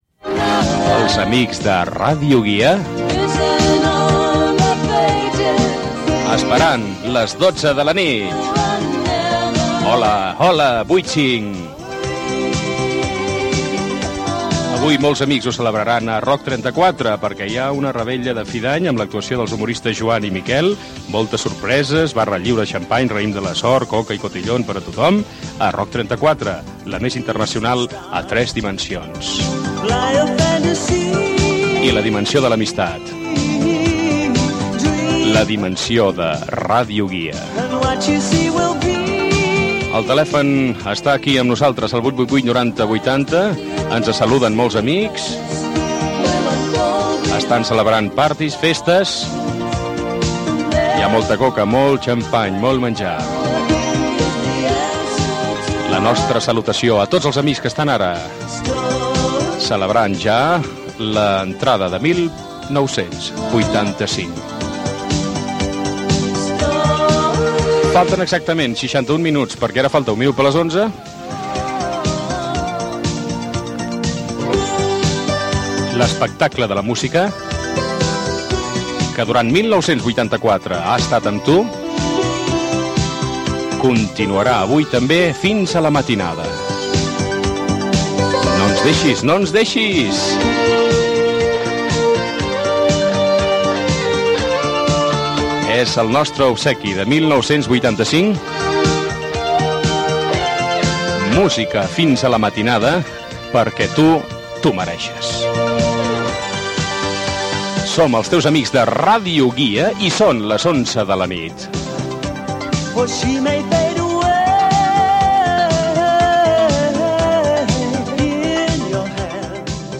Fragment de l'espai de cap d'any: publicitat, telèfon i salutació quan falta una hora del canvi d'any.
Musical
FM